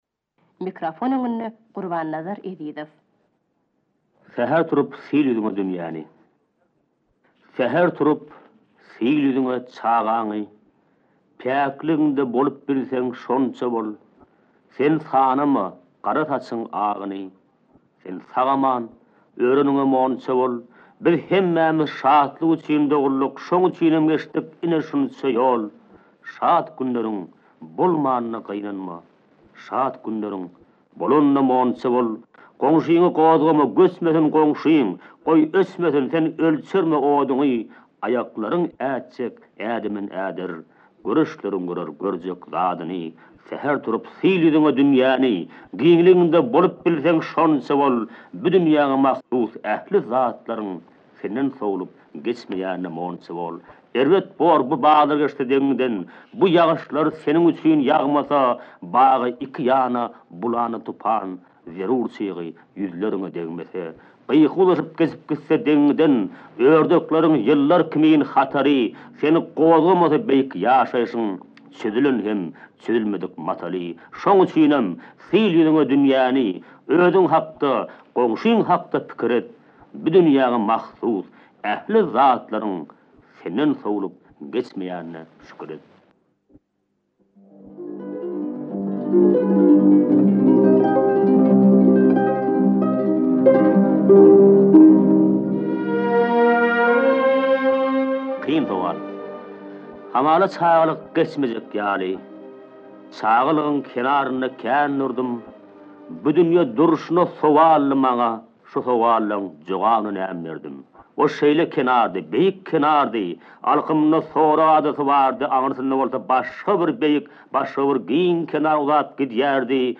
Biraz gyssanyp okaýan eken. Onda-da Şahyryň özünden eşitmek başgaça.